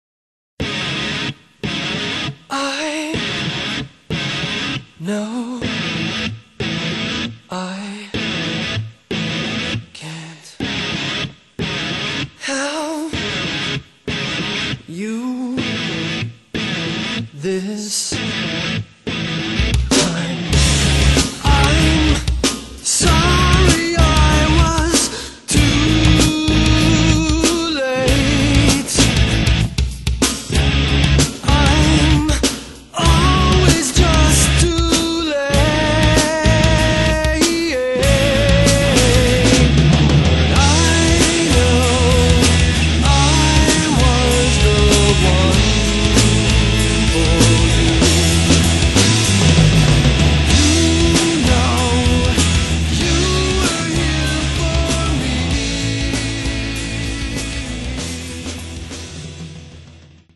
Modern Rock and Pop